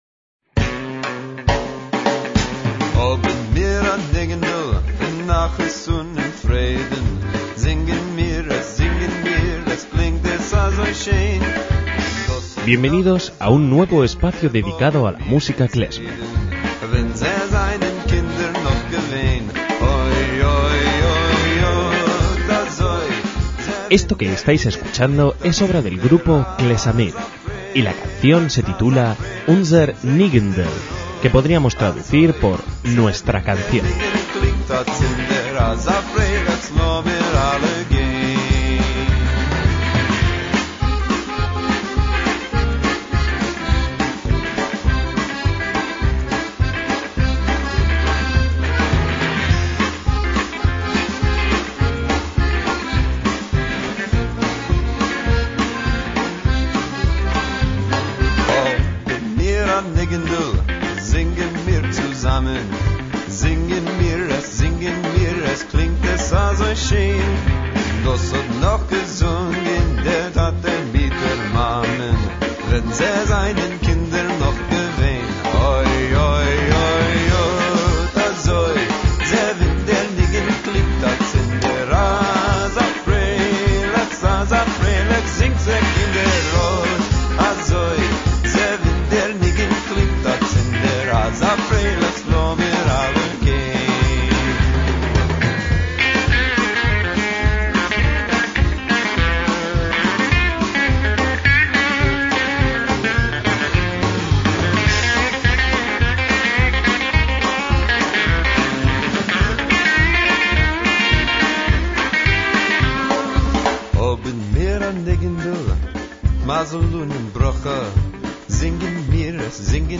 MÚSICA KLEZMER
clarinete, guitarra, mandolina y voz
flauta, piano, acordeón y voces
contrabajo y bajo
batería y percusión
trombón, melódica y DJ